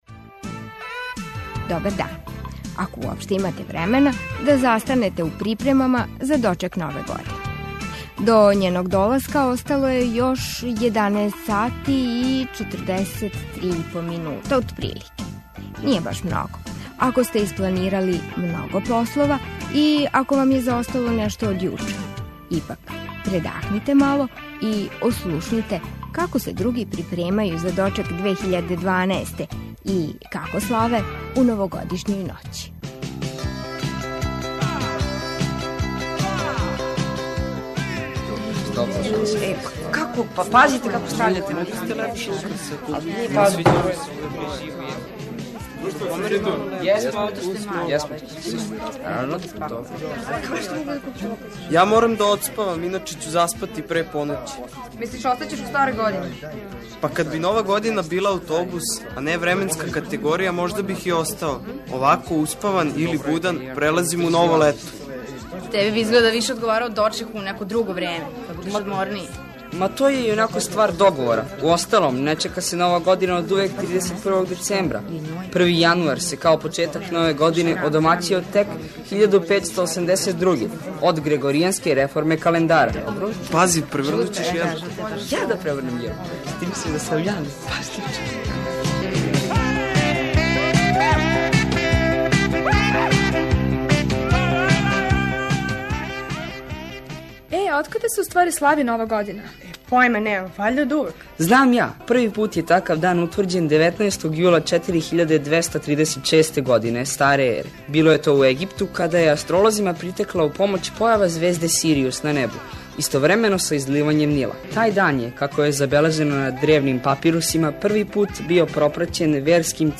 Тинејџери из разних крајева света усред су припрема за новогодишњу журку. Четрдесет пет минута, колико траје емисија, ослушкујемо њихове разговоре.